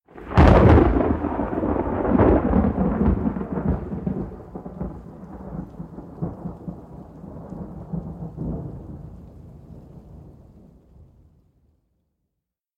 Lightning1.mp3